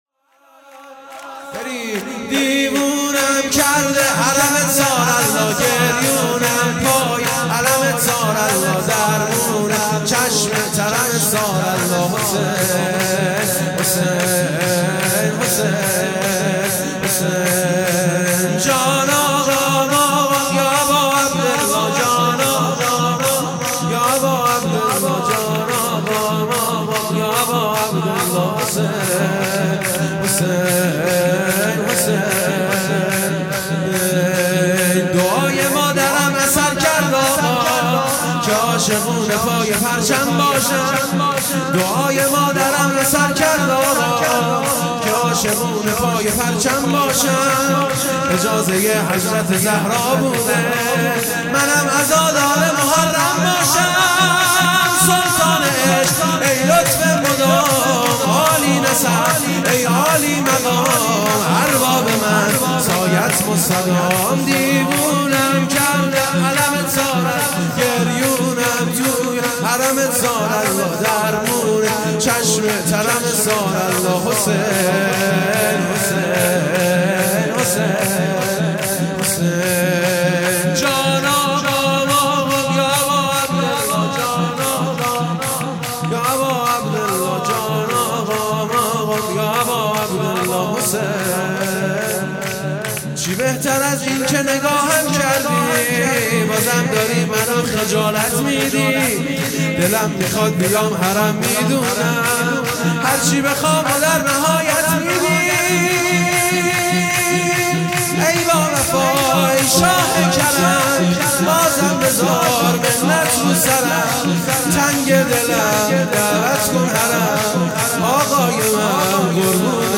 شور
میلاد امام حسن عسکری(ع) - سه شنبه١٢آذرماه١٣٩٨